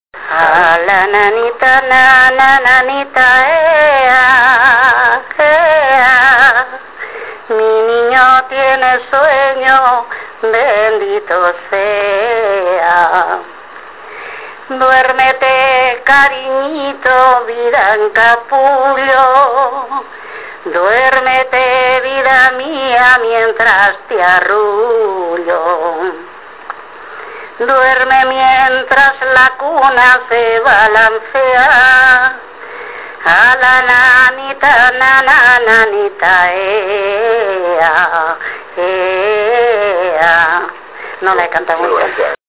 Las canciones de cuna en La aldea.